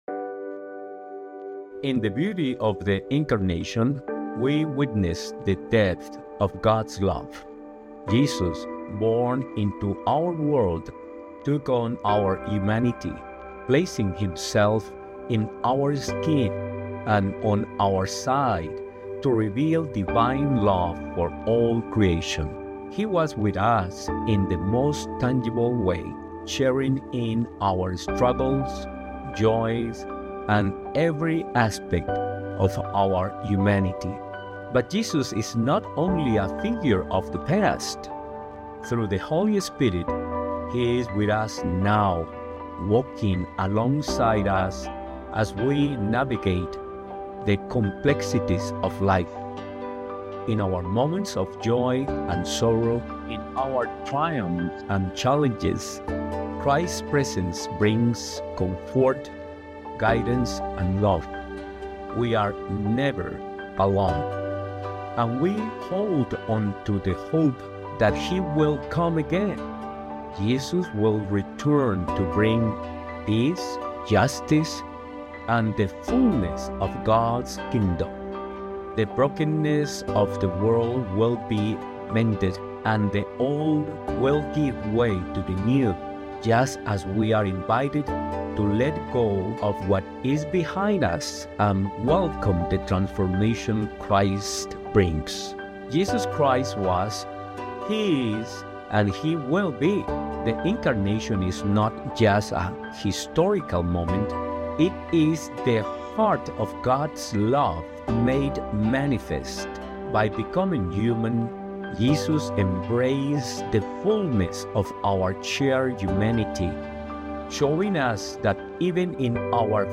Sermon for December 22, 2024 – Fourth Sunday of Advent